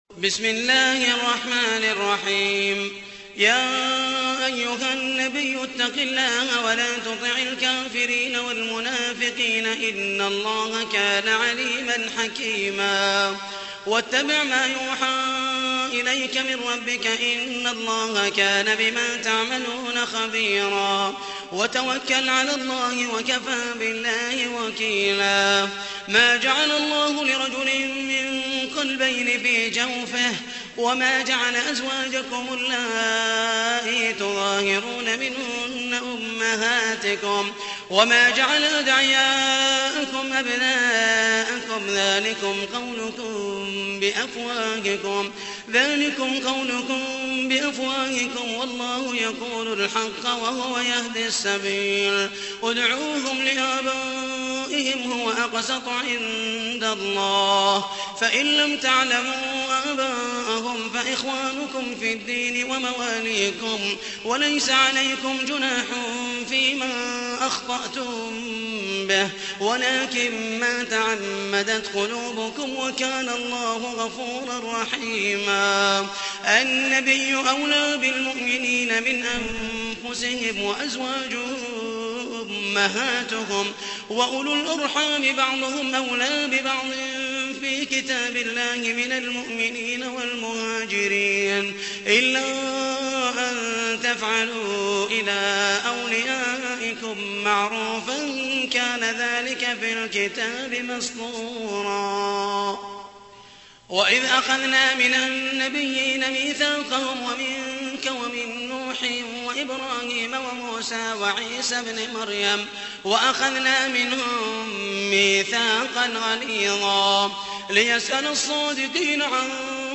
تحميل : 33. سورة الأحزاب / القارئ محمد المحيسني / القرآن الكريم / موقع يا حسين